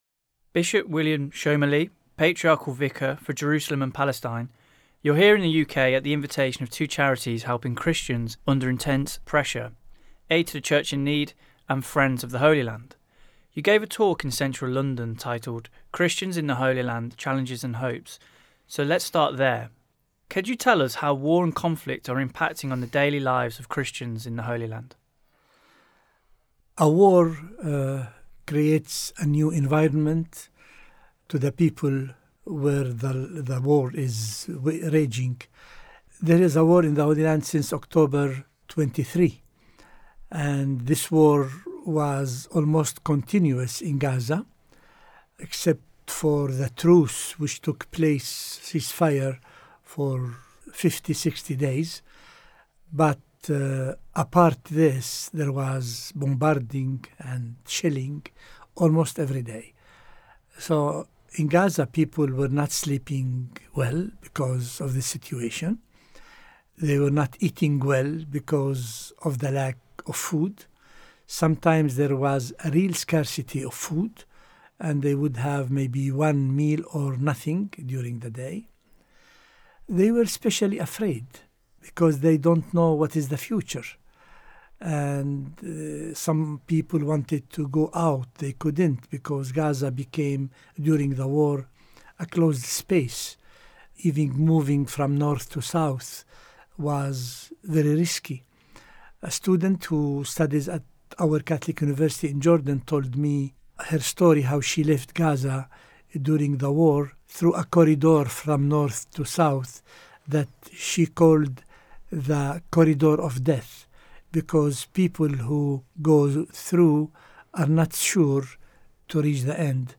Bishop William Shomali, Patriarchal Vicar for Jerusalem and Palestine, joins us for this Catholic News podcast. The Bishop was in the UK at the invitation of two charities helping Christians under intense pressure – Aid to the Church in Need and Friends of the Holy Land.